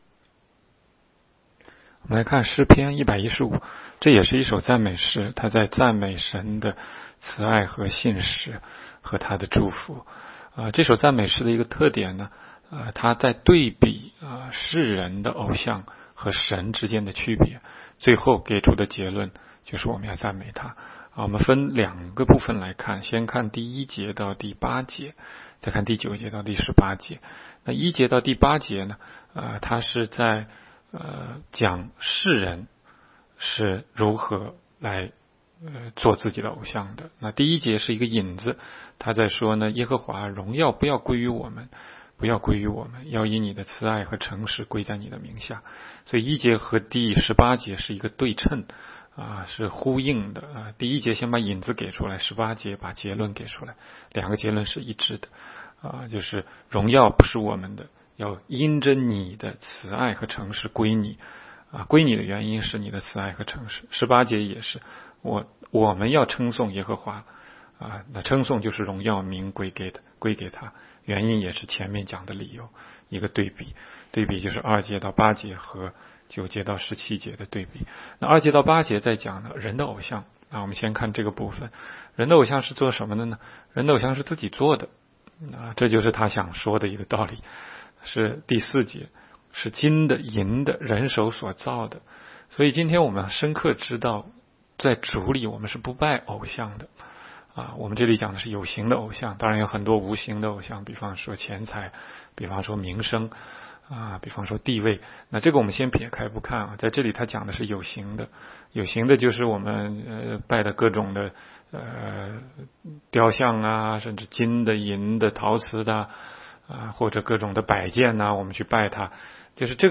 16街讲道录音 - 每日读经 -《 诗篇》115章